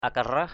/a-ka-raɦ/ (Ar. akhirah) (d.) cõi âm, thế giới bên kia = enfers. world of the dead, afterlife. ahar siam pak akarah (UMR) ahR s`’ pK akrH miếng bánh tốt lành để thế...